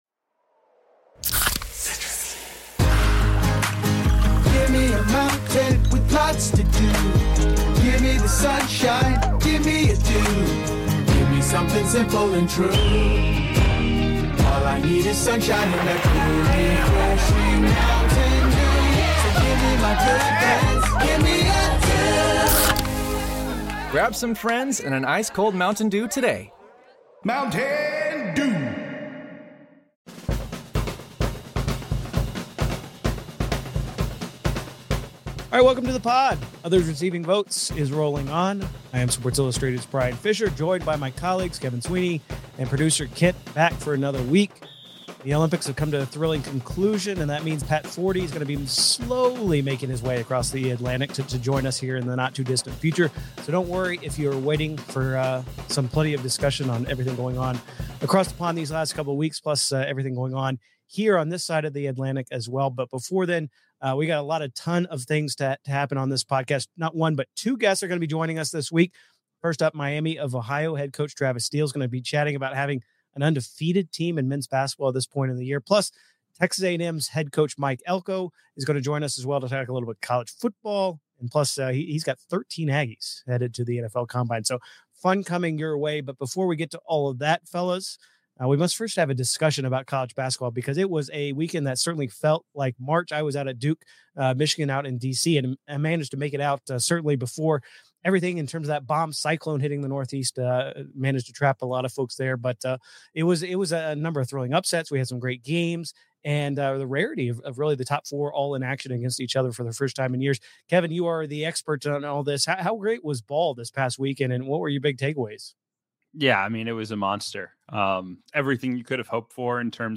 Also, the guys interview Texas A&M Aggies football coach Mike Elko (33:27) to break down his successful past season and what lies ahead for his 2026 team.